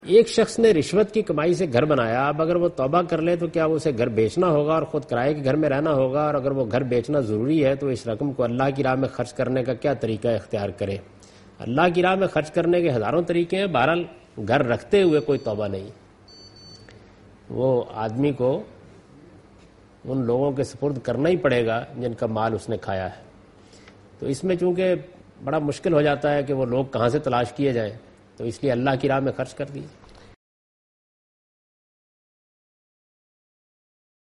Question and Answers with Javed Ahmad Ghamidi in urdu